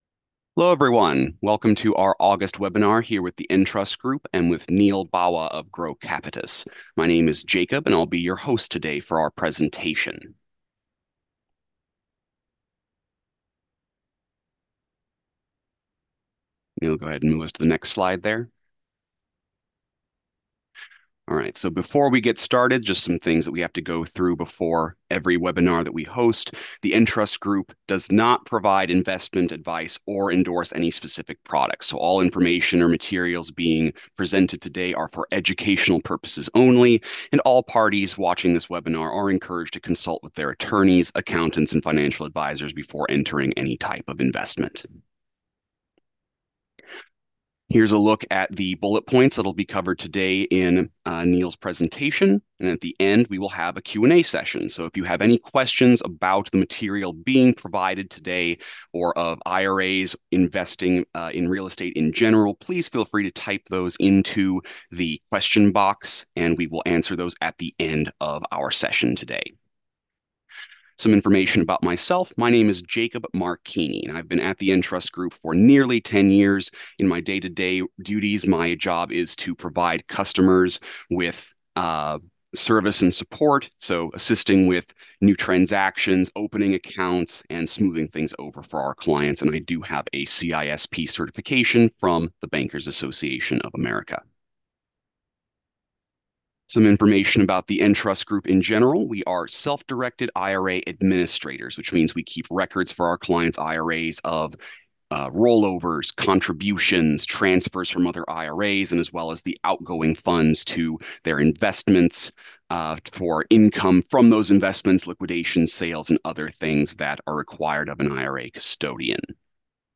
For a data-driven webinar exploring the latest trends and new developments shaping the industry this year, check out this midyear breakdown!